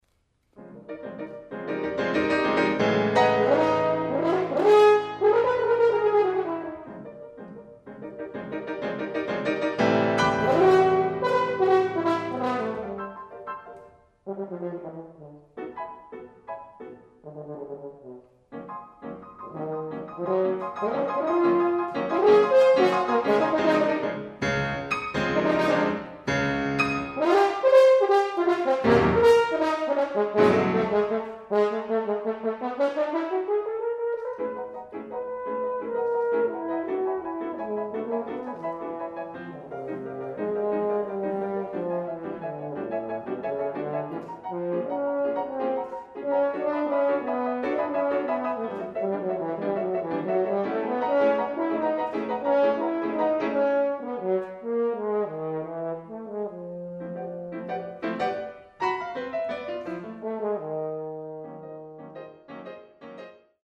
A Sonata for horn and piano.